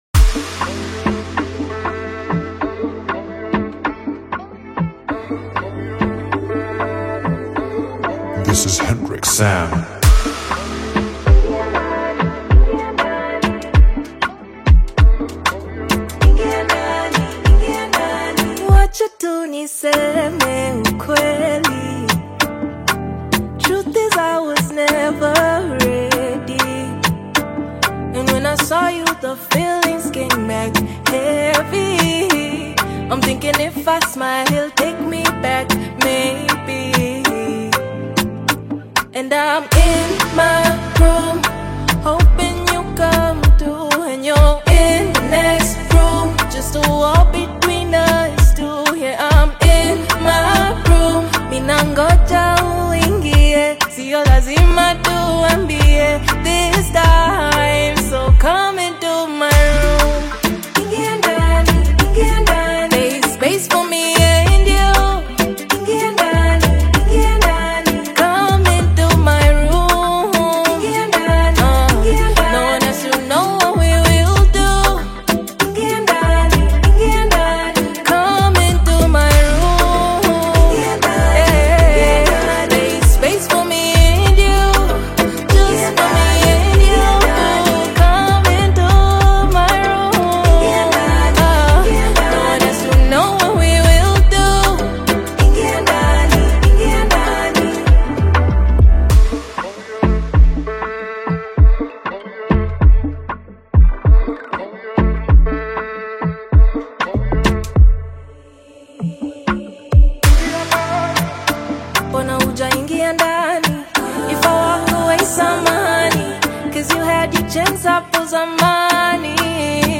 soulful Afro-Pop/R&B single
warm vocal tone and modern Afro-fusion sound